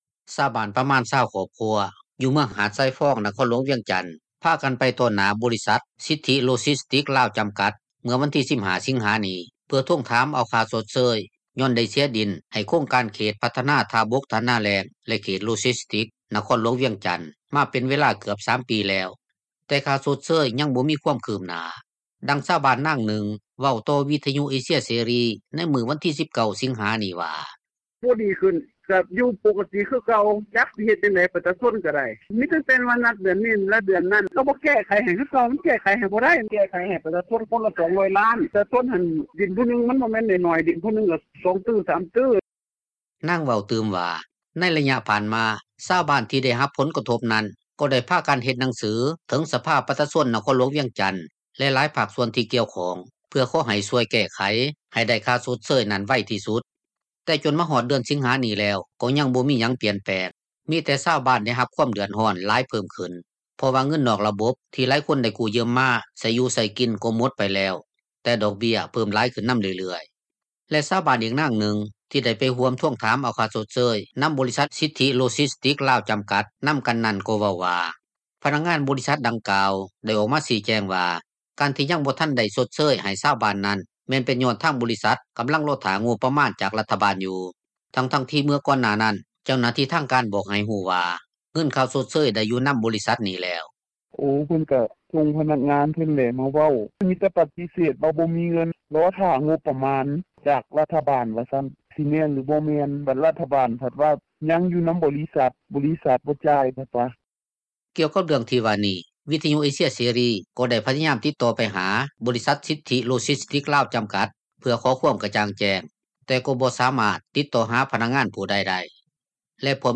ດັ່ງຊາວບ້ານ ນາງນຶ່ງເວົ້າຕໍ່ວິທຍຸເອເຊັຽເສຣີ ໃນມື້ວັນທີ 19 ສິງຫານີ້ວ່າ: